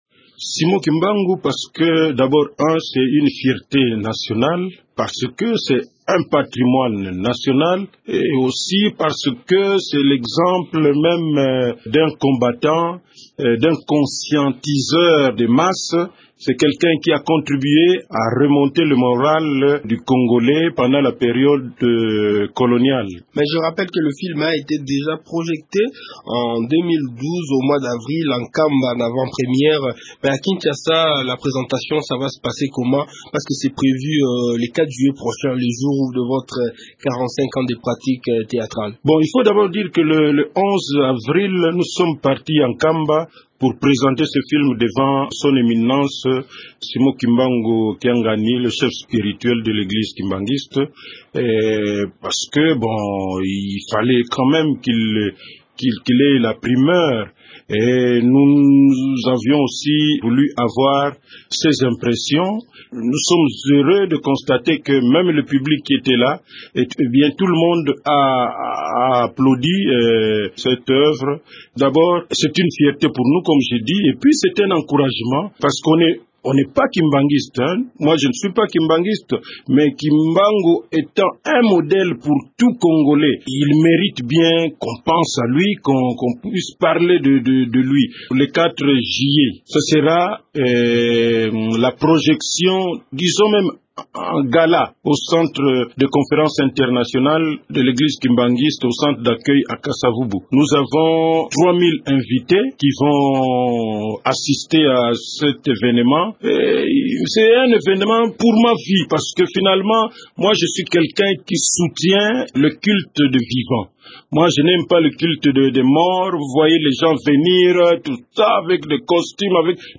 Dans cet entretien avec